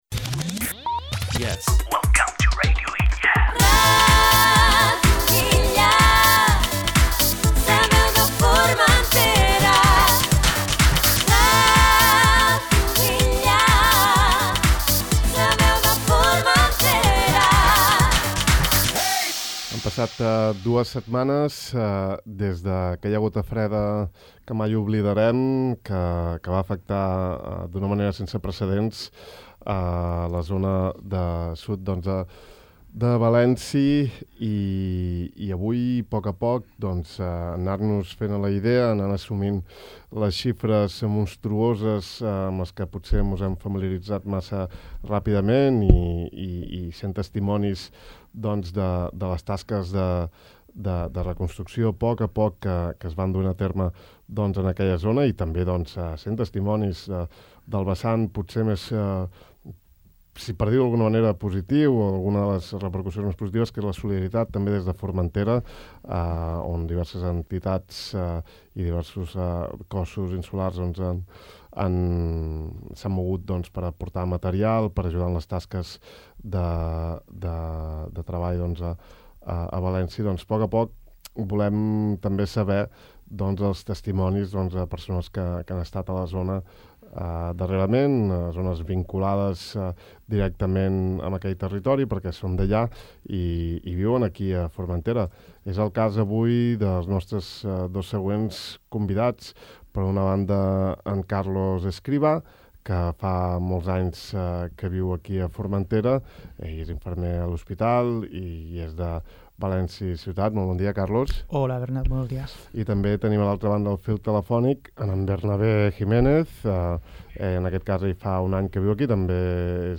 Les conseqüències de la gota freda al País Valencià continuen marcant el dia a dia dels pobles afectats per la riuada a la comarca de l’Horta Sud. Així ens ho han explicat en entrevista dos veïns de Formentera d’origen valencià que recentment han passat uns dies a la zona ajudant.